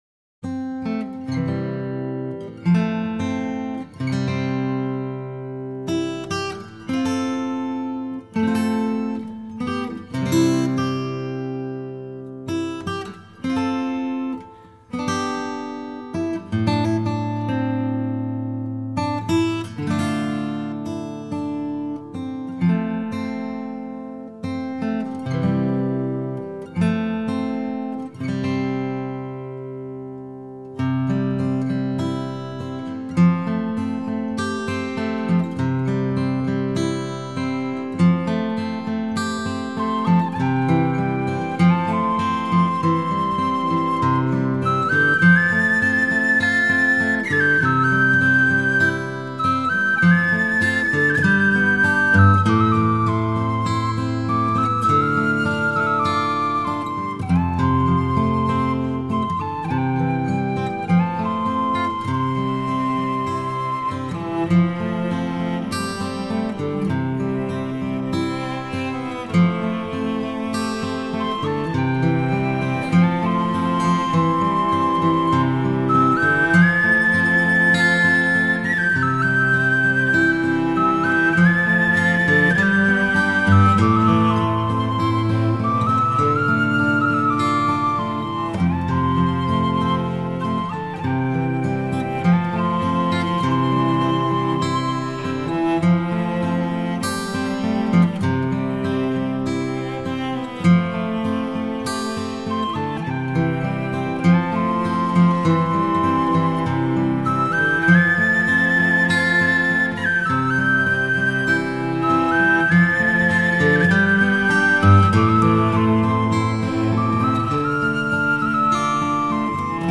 кельтская муз.,гитара флейта